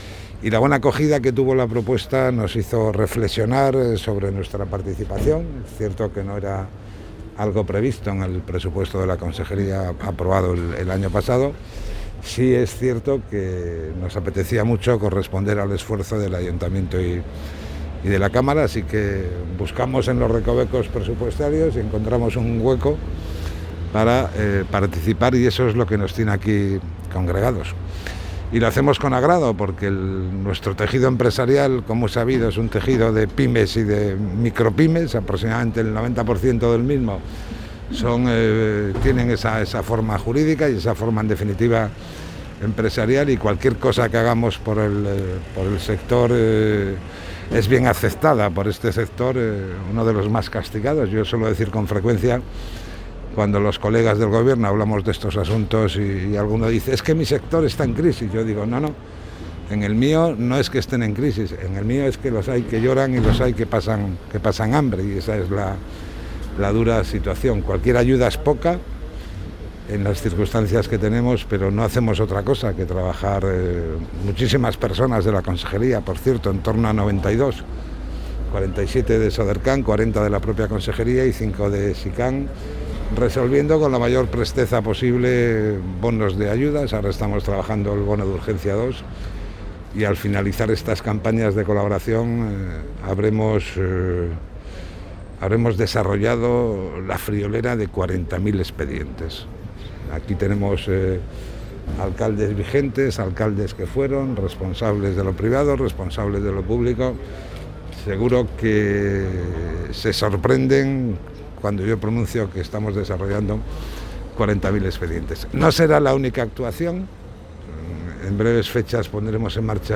Audio de Javier López Marcano